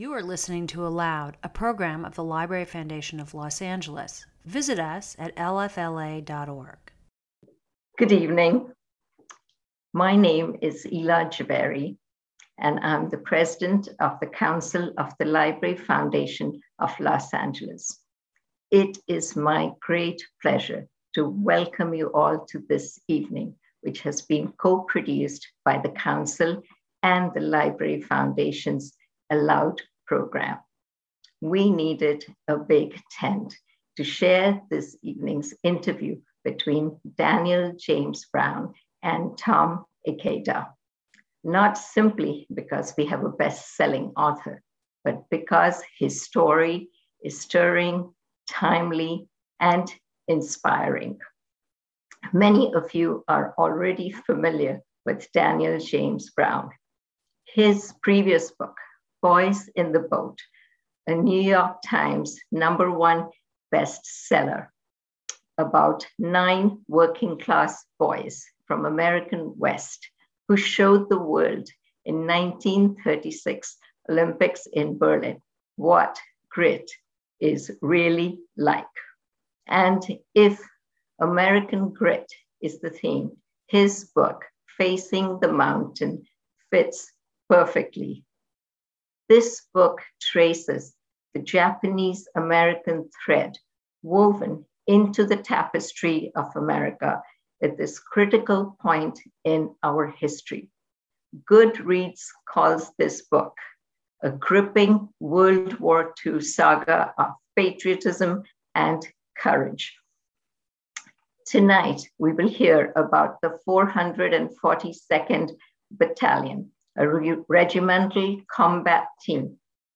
The Library Foundation welcomes the #1 New York Times bestselling author of The Boys in the Boat for a conversation about his latest masterful work. Daniel James Brown’s new World War II saga, Facing the Mountain, follows a special Japanese-American Army unit that overcame brutal odds in Europe.